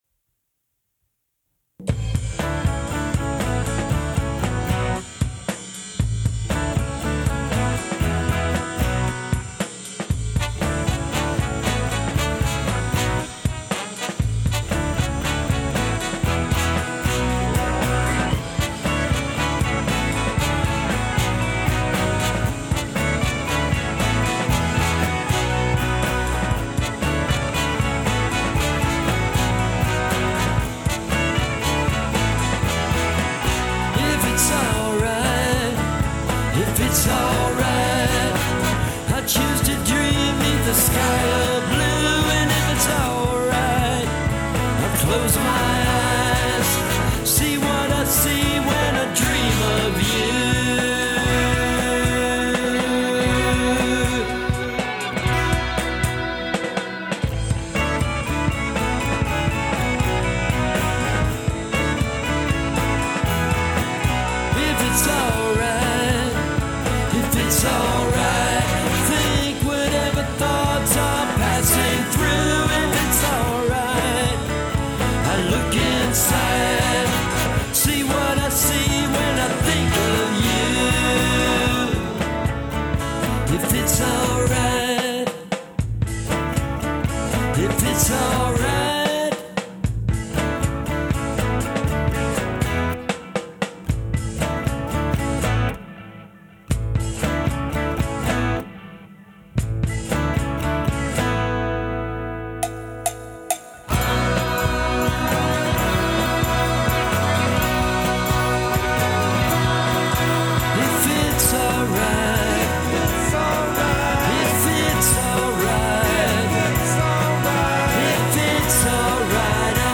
trombone/vocals
trumpet/vocals
electric guitar